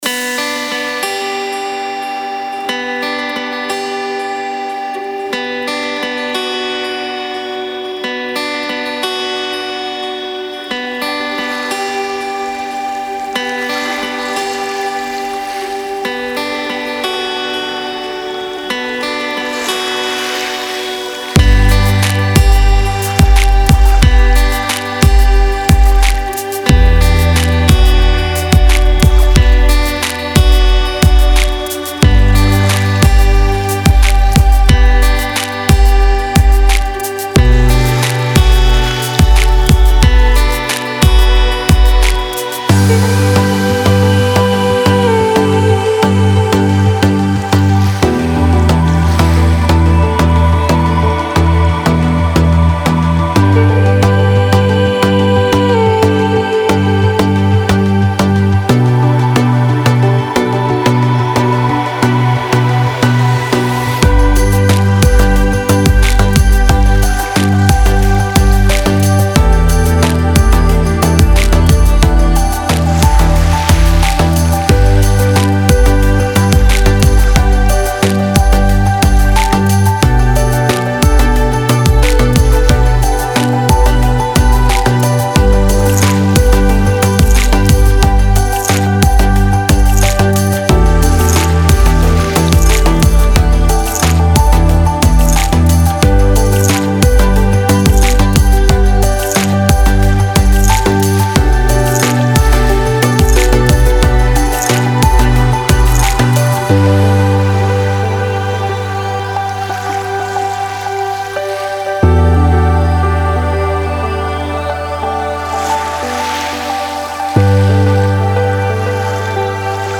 Стиль: Chillout / Lounge / Ambient / Downtempo